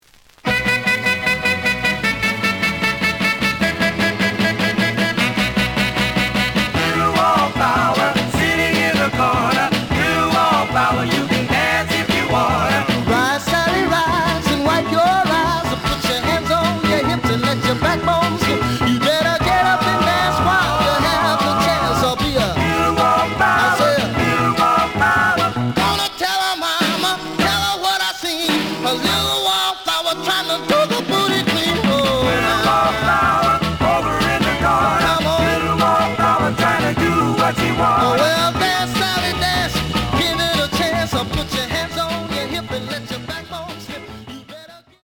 The audio sample is recorded from the actual item.
●Genre: Rhythm And Blues / Rock 'n' Roll
B side plays good.)